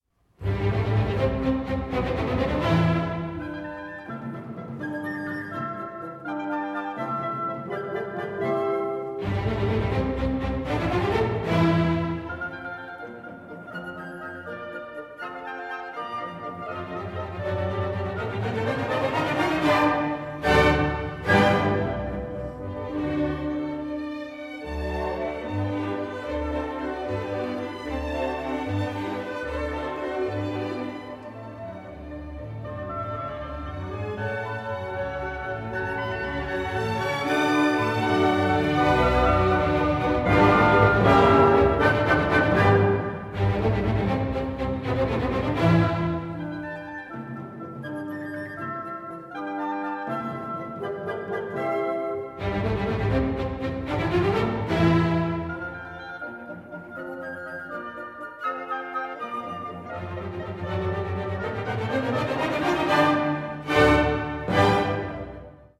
5 Scherzo. Allegro vivace - Trio